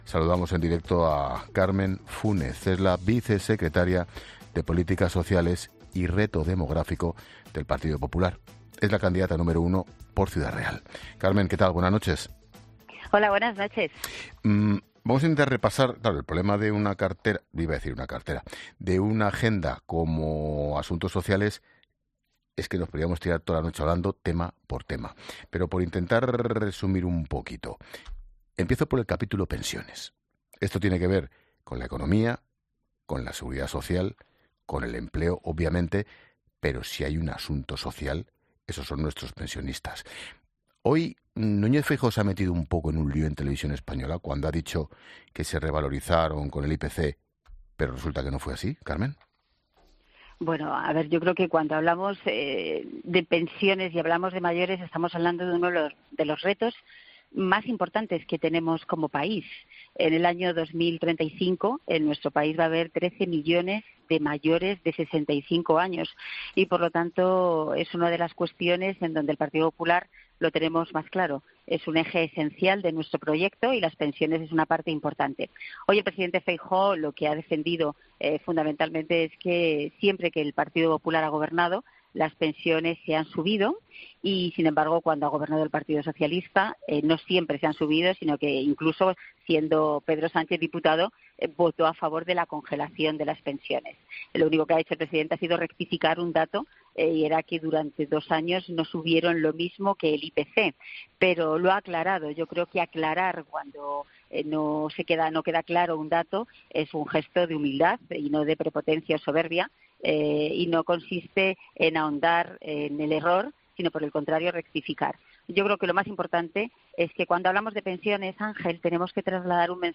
Escucha la entrevista de Expósito a Carmen Fúnez, vicesecretaria de Política Social del PP